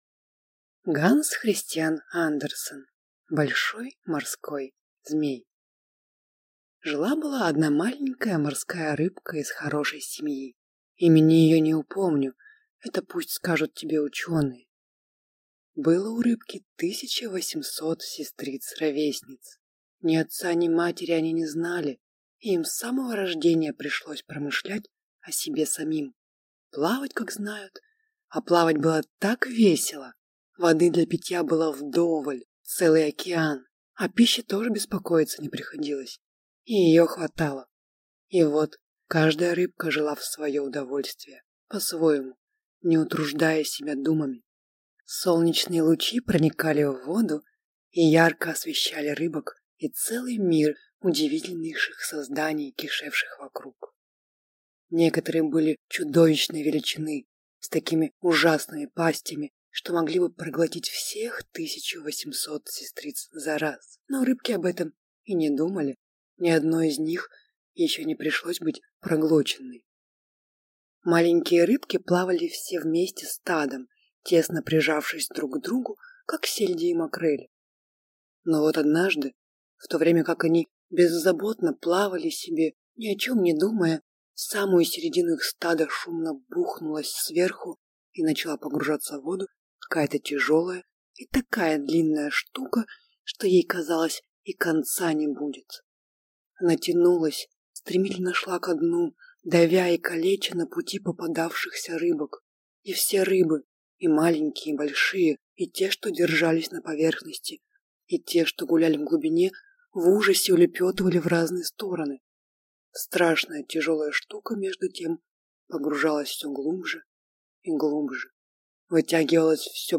Аудиокнига Большой морской змей | Библиотека аудиокниг